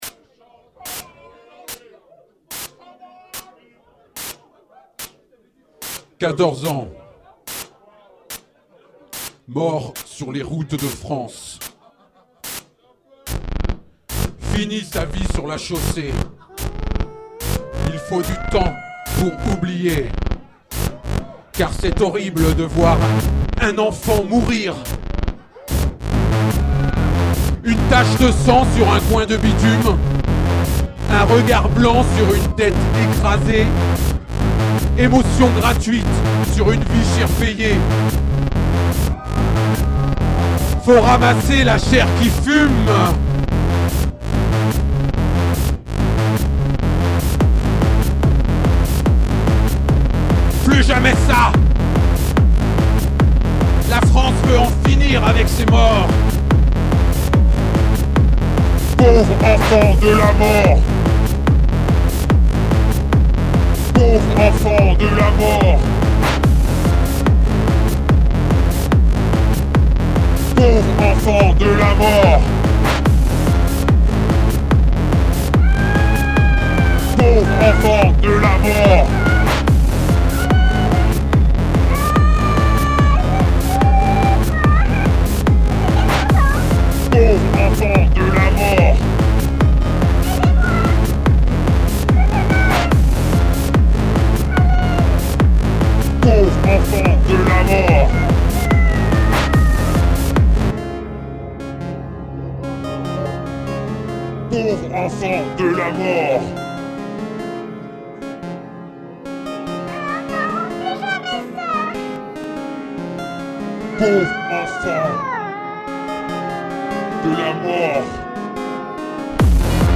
LIVE IN BLOCKAUS DY10